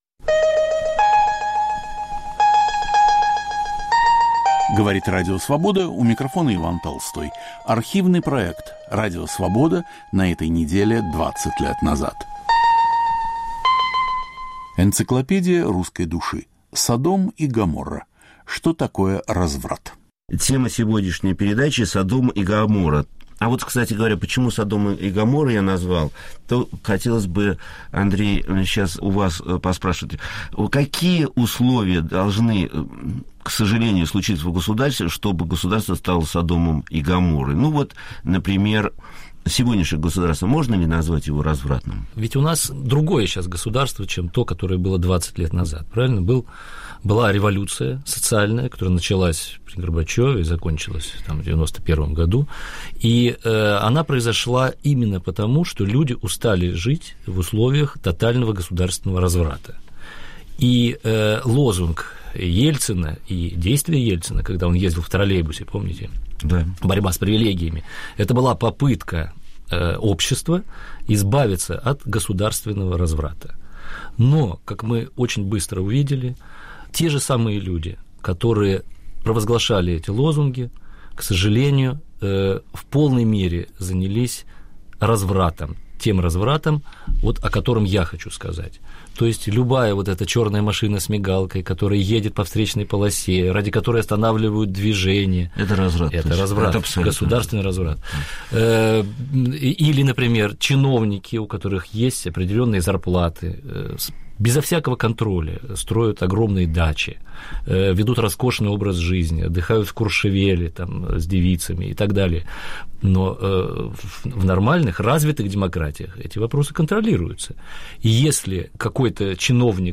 Понятие разврата в разных культурах и в представлениях каждого человека. В студии социолог Игорь Кон и литератор Андрей Мальгин. Автор и ведущий Виктор Ерофеев.